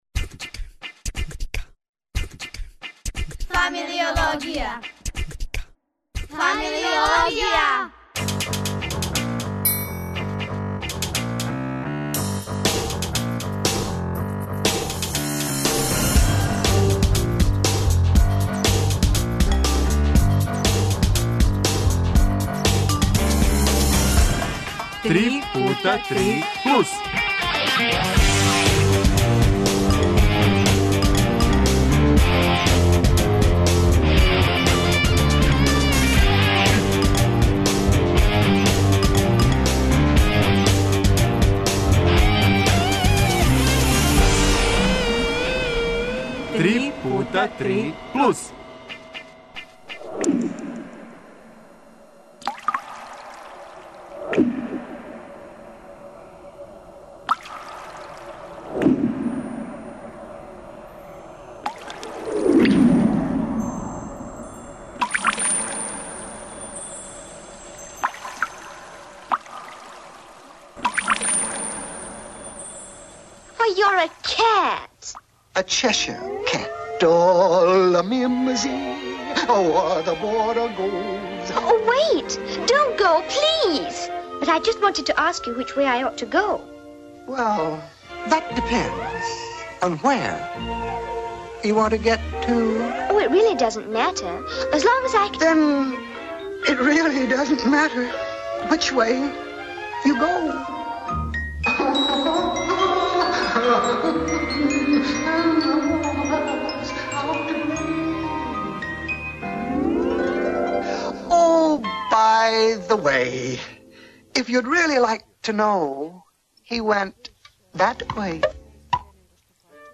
Да бисмо најавили Златну кулису први регионални аматерски академски позоришни фестивал стигли смо у Крагујевац.